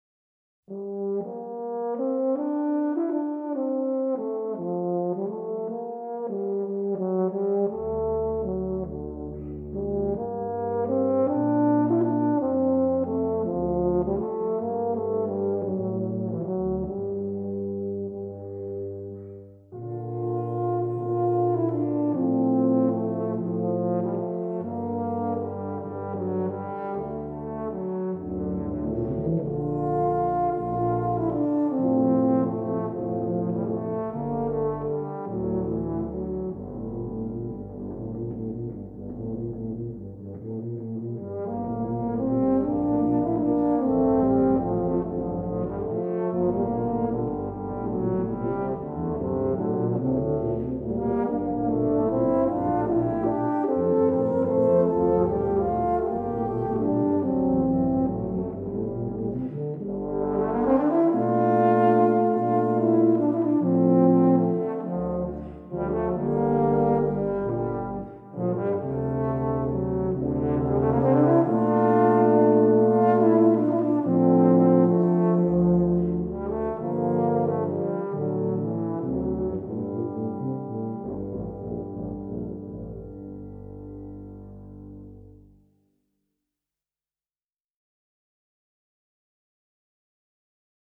For Tuba Quartet (EETT), Composed by Traditional.